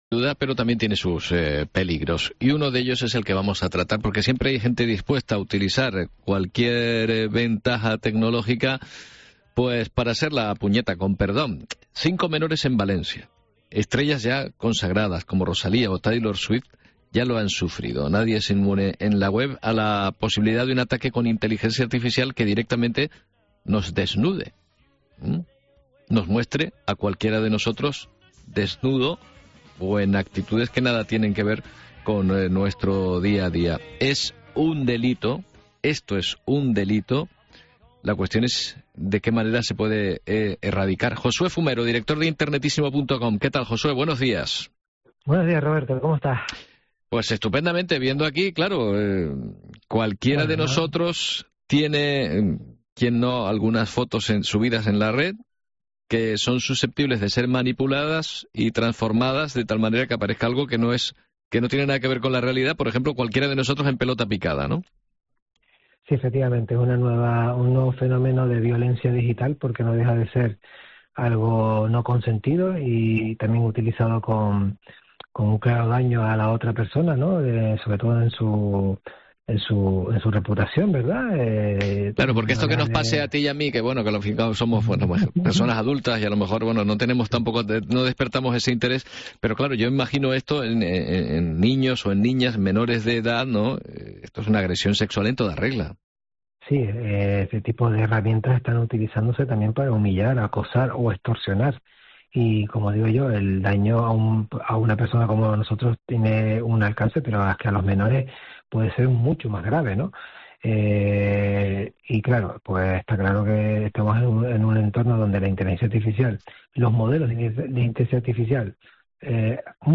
Esta semana, en el programa La mañana de COPE Canarias, hablamos sobre este nuevo riesgo en Internet